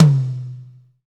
Index of /90_sSampleCDs/Northstar - Drumscapes Roland/DRM_Pop_Country/KIT_P_C Wet 1 x
TOM P C H1BR.wav